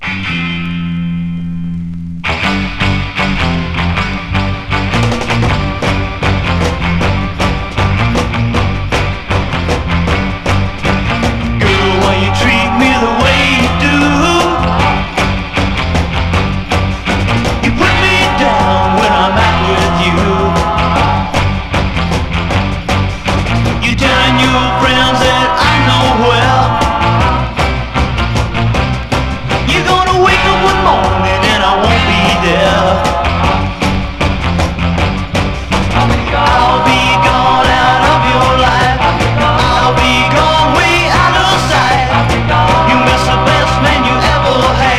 盤面から溢れる猛る音、それぞれに気合いが漲る様が痛快とも思える心地良さ。
Rock, Garage, Psychedelic　France　12inchレコード　33rpm　Mono